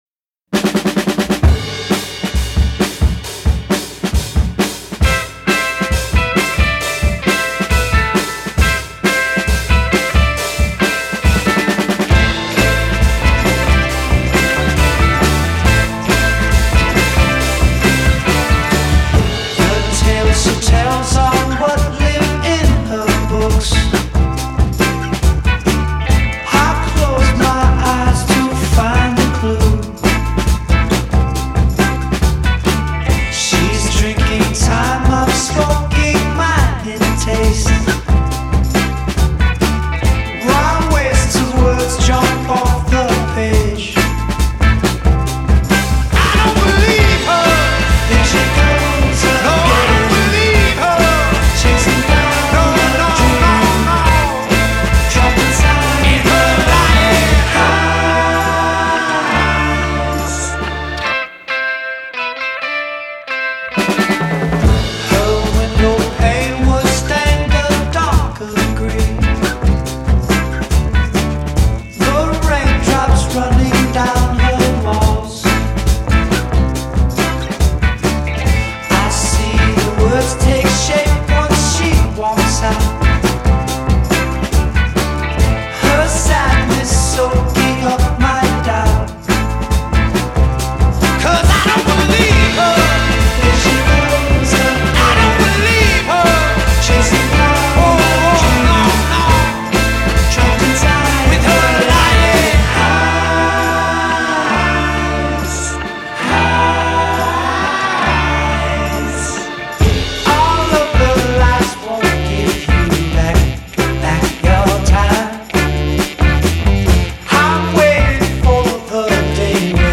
Style: Funk, Soul, Psychedelic